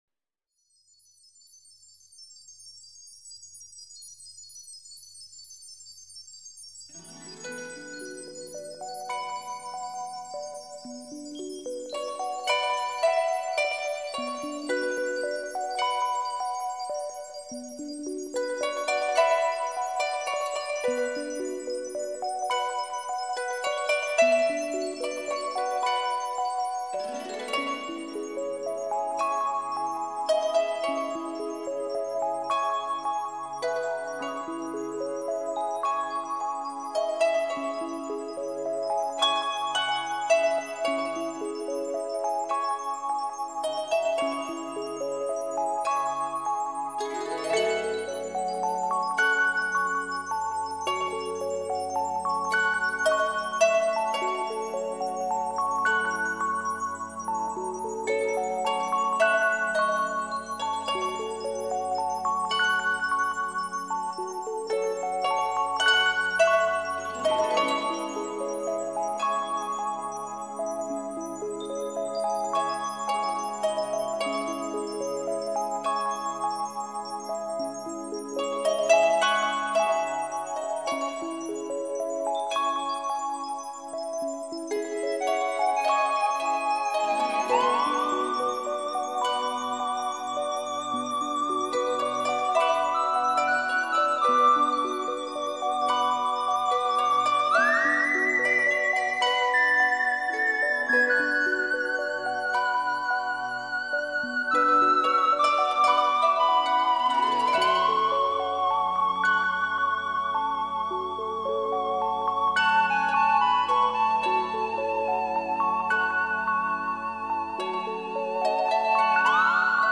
新世纪 你是第9240个围观者 0条评论 供稿者： 标签：,
乐声悠扬，心随着天籁之音一起飞。
行云流水般的音乐有如兰花玉指，为我们拂去喧嚣尘世遗落于心头的尘埃。
沉静而宏大的音律，直达人的内心，触摸人的灵魂。